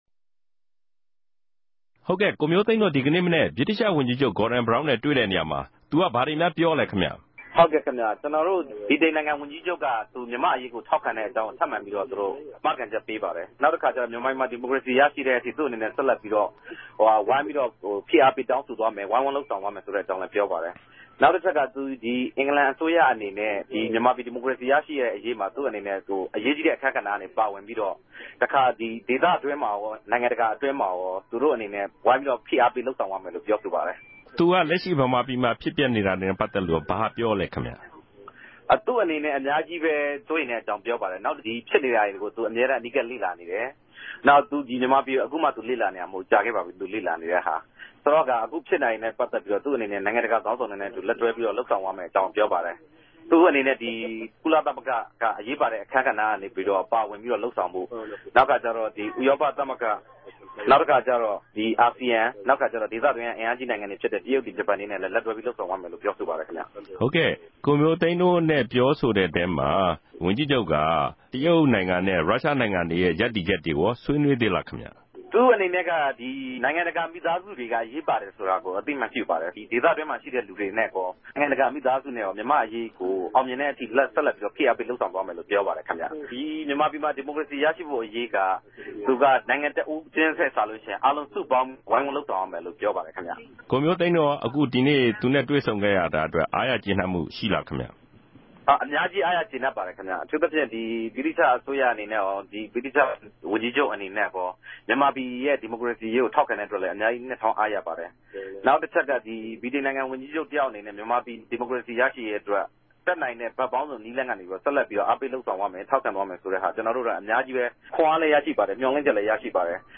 လန်ဒန်္ဘမိြႛ သိန်းူမစ်ကမ်းနဘေး ူမန်မာ့အရေး ဆ္ဋိံူပပြဲကနေ